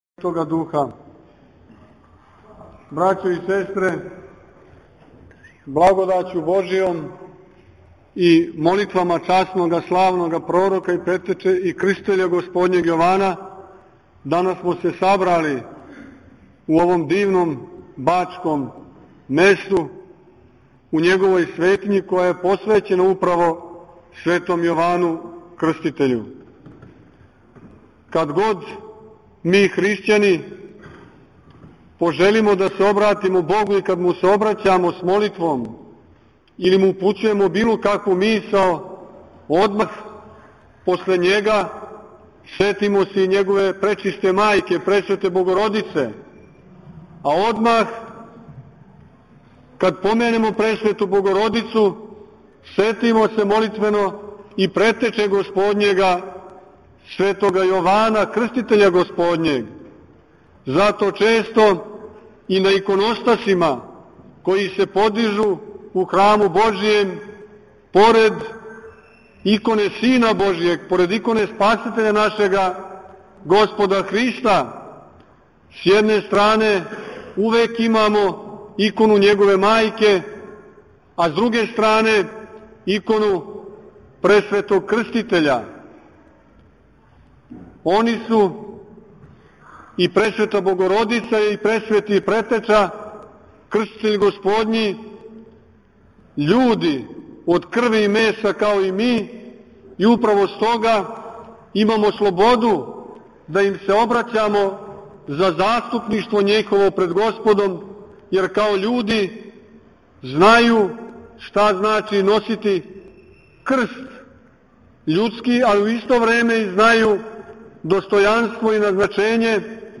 Његово Преосвештенство Епископ јегарски Господин Порфирије, после вечерњег богослужења и резања славског колача, поучио је верне надахнутом беседом.